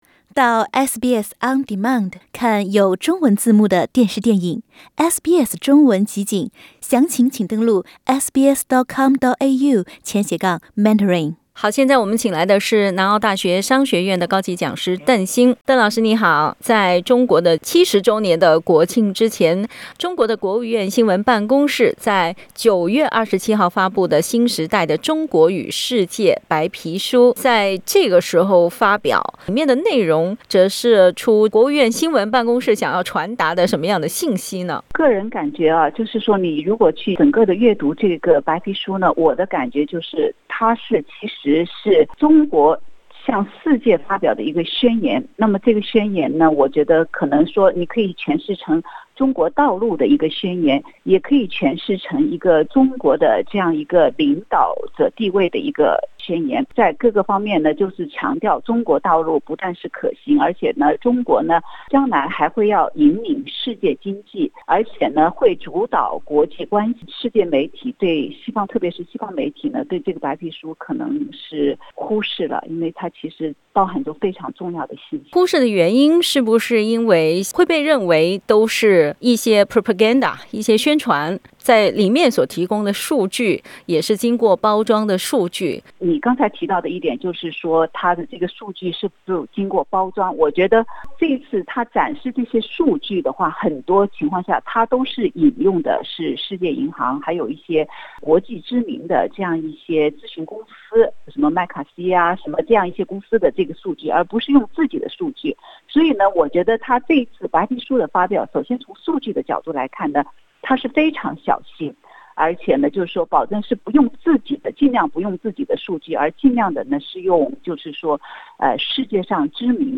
（本节目为嘉宾观点，不代表本台立场。）